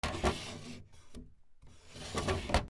/ M｜他分類 / L05 ｜家具・収納・設備
『シュー』